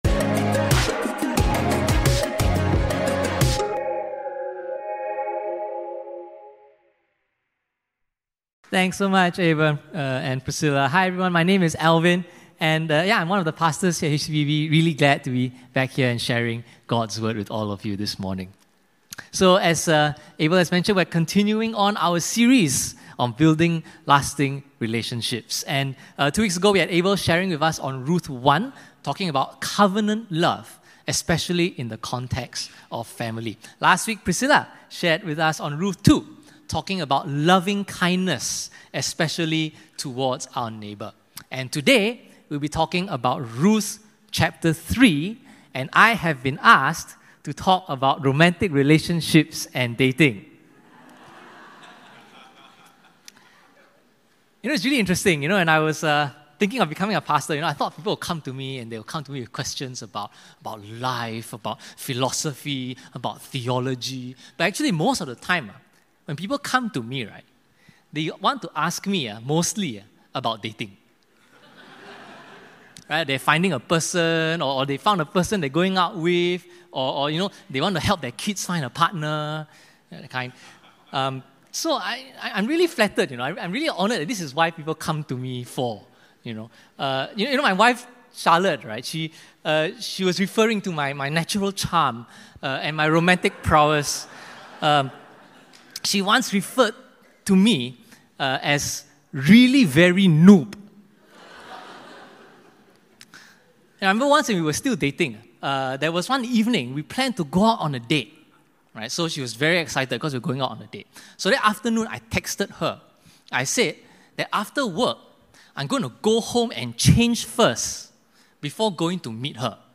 SERMON+ENG_16+nov.MP3